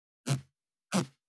415,ジッパー,チャックの音,洋服関係音,ジー,バリバリ,カチャ,ガチャ,シュッ,パチン,ギィ,カリ,
ジッパー効果音洋服関係